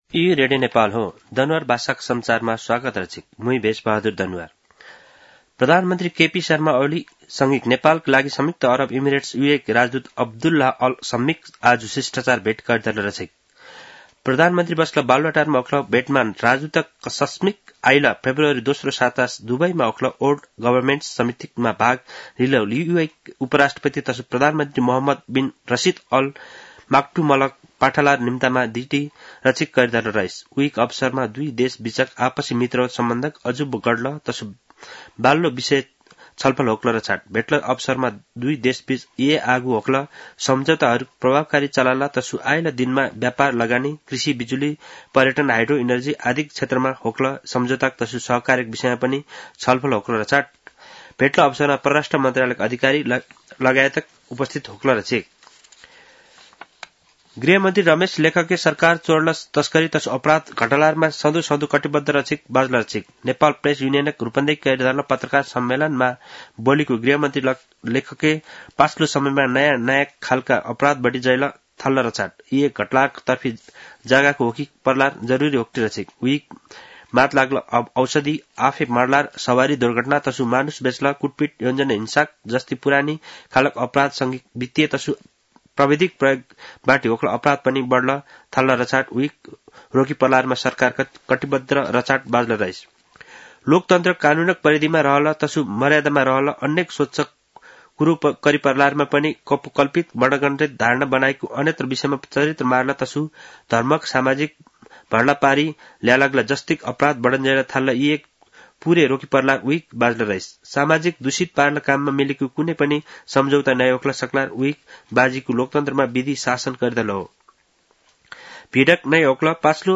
दनुवार भाषामा समाचार : ४ पुष , २०८१